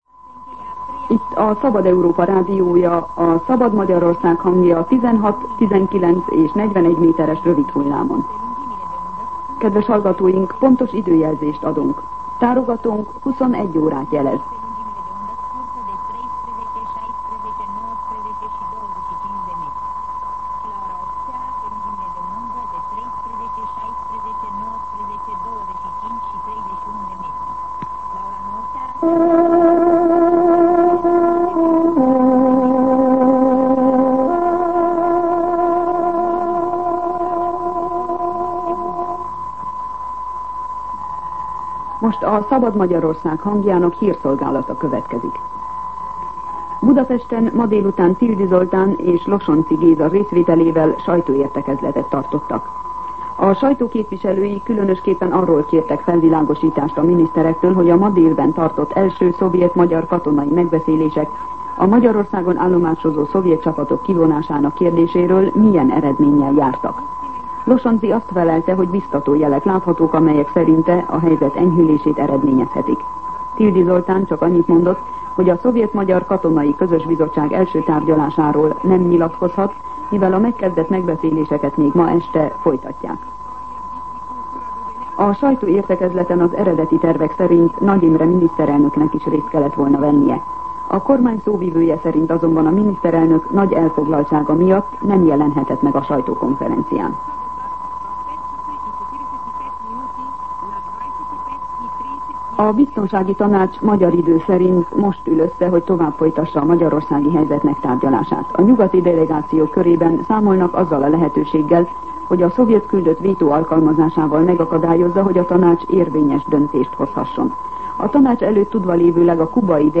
21:00 óra. Hírszolgálat